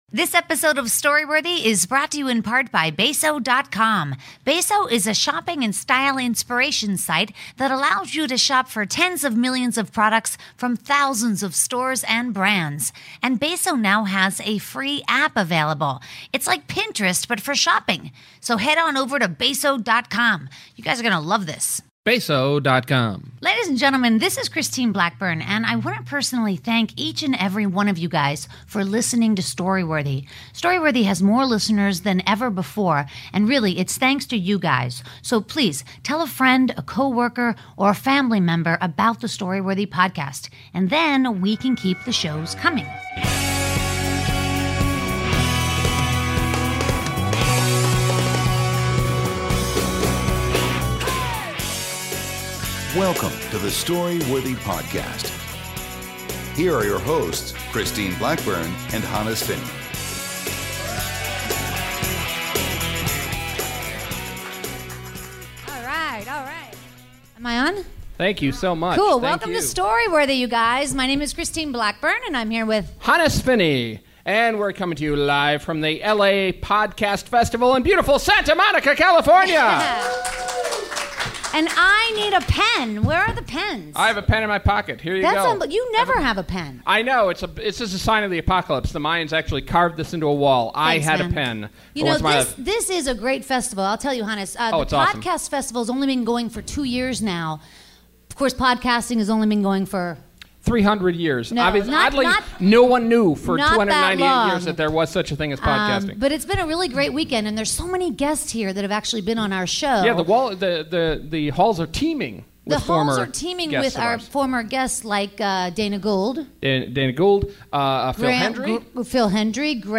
Then they rock out.